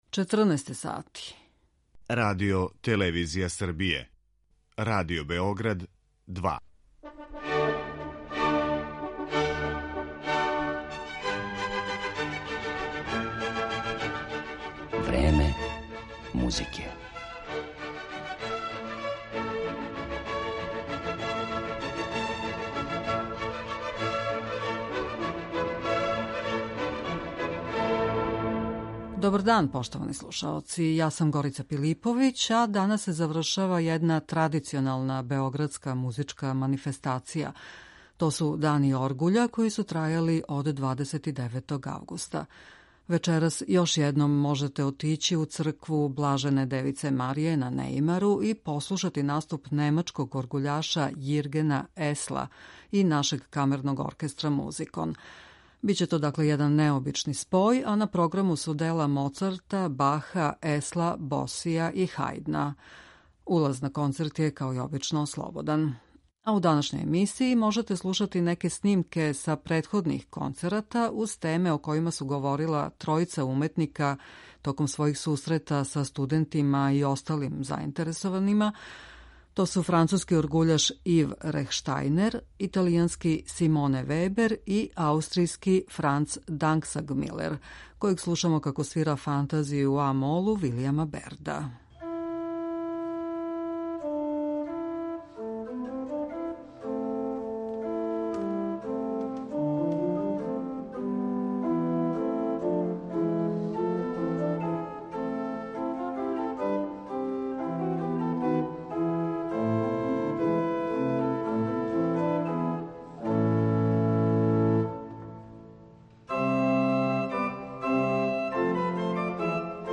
Традиционални београдски међународни Дани оргуља завршавају се вечерас, а у данашњој емисији Време музике можете слушати репортажу са овог фестивала.